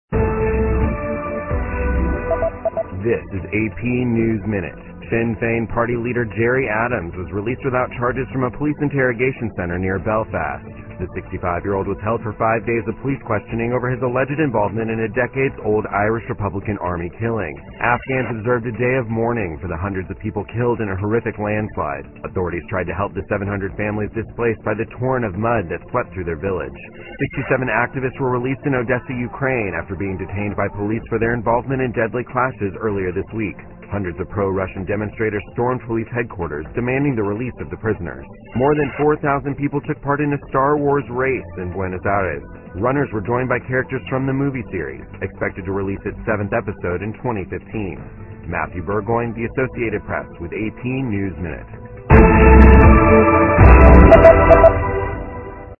美联社新闻一分钟 AP 2014-05-07 听力文件下载—在线英语听力室